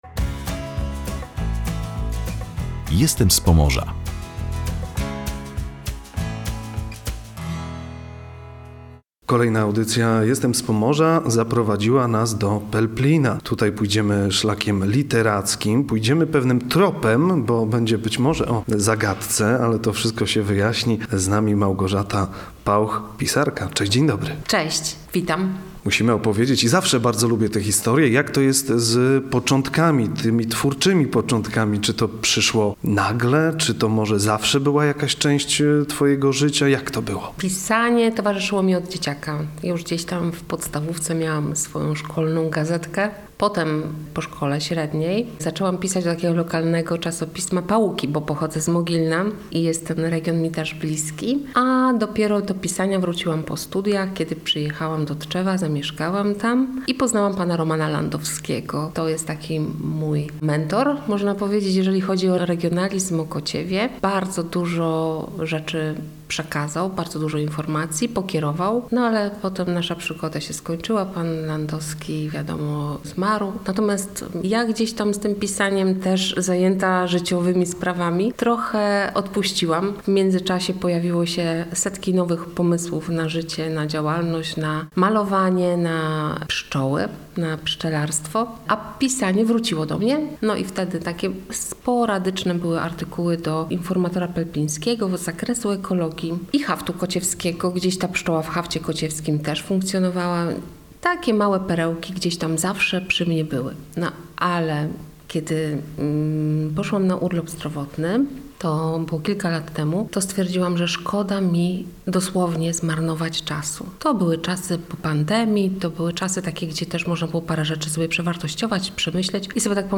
W rozmowie opowiada o swoich twórczych początkach, inspiracjach oraz o tym, jak historia regionu i codzienne życie w Pelplinie wpłynęły na jej literacką drogę.